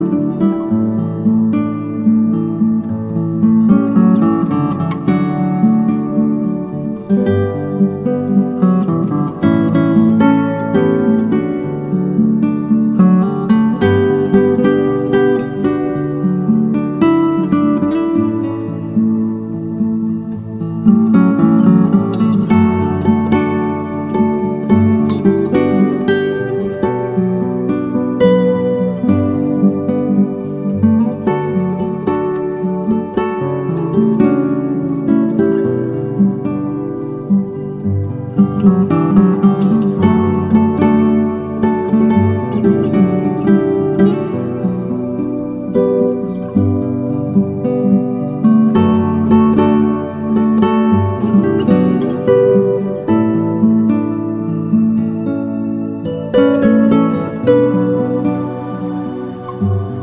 Instrumentalversionen beliebter Lobpreislieder
• Sachgebiet: Praise & Worship